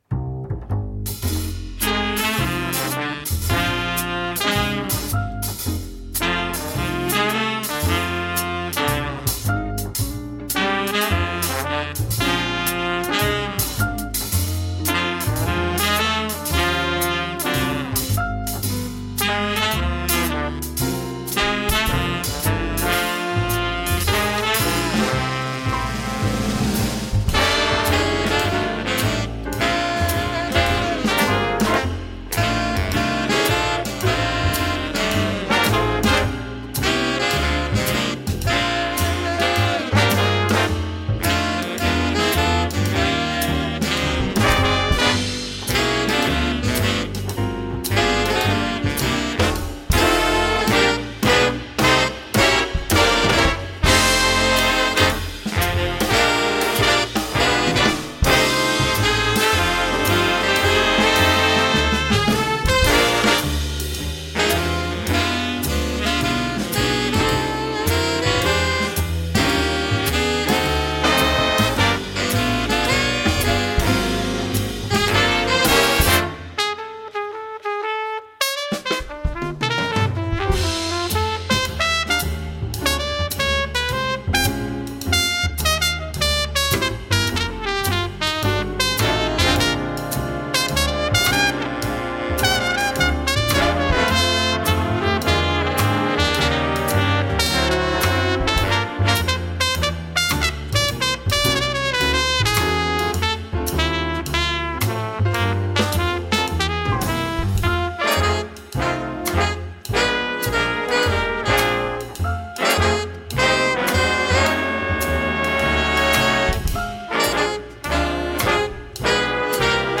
Voicing: Jazz Band